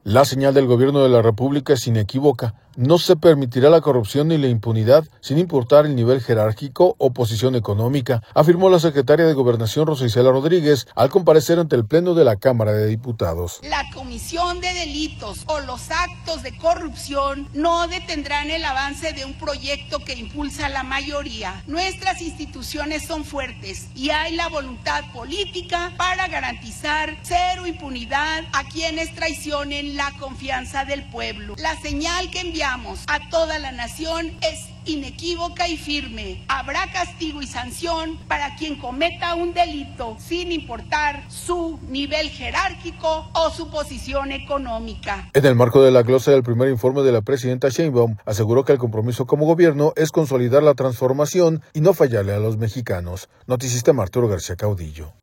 Secretaría de Gobernación comparece ante diputados